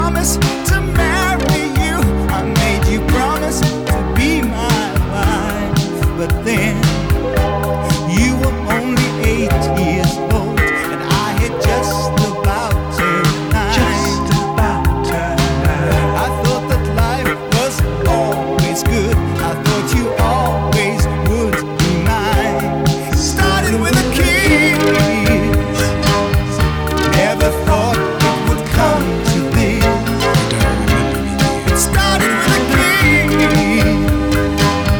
# Soft Rock